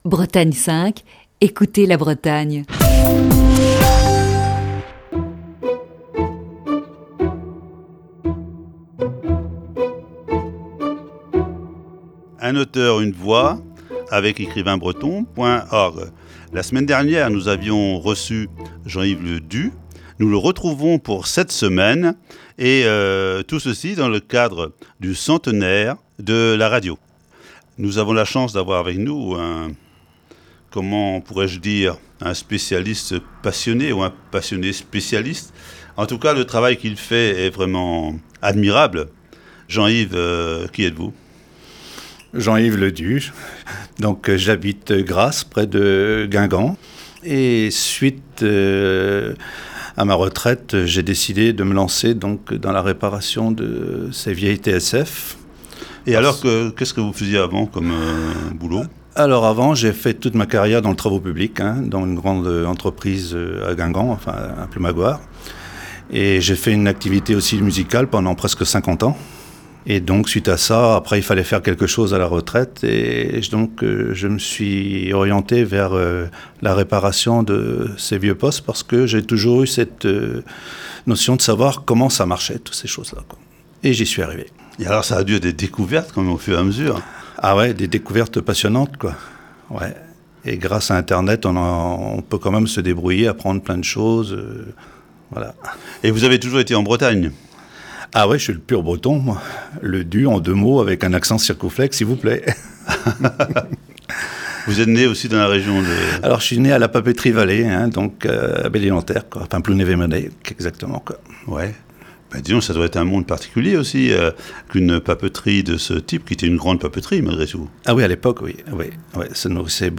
Ce lundi, première partie de cette série d'entretiens.